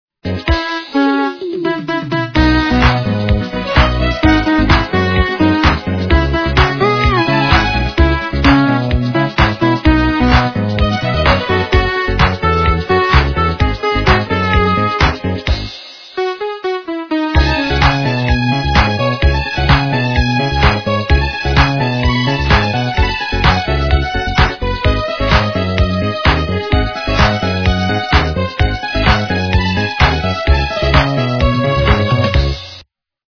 - русская эстрада
качество понижено и присутствуют гудки